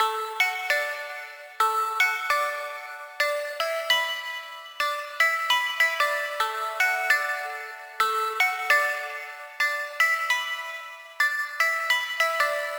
150BPM Lead 06 Dmaj.wav